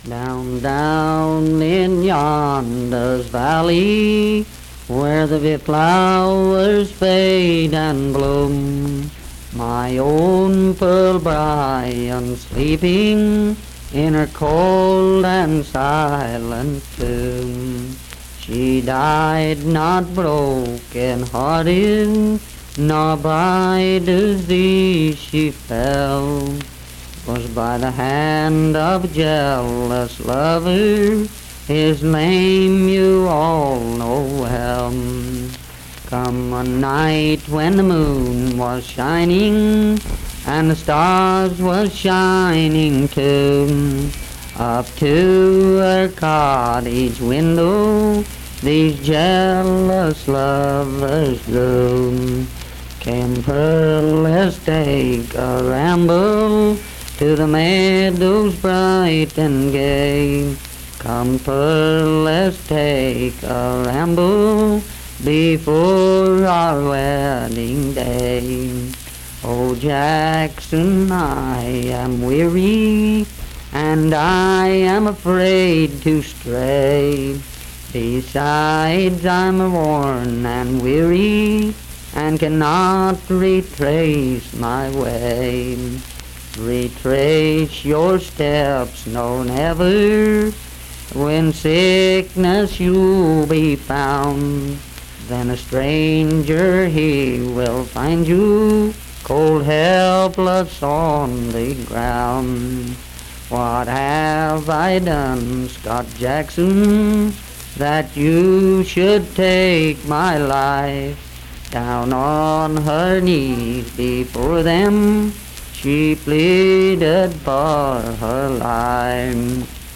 Unaccompanied vocal music performance
Voice (sung)
Wirt County (W. Va.)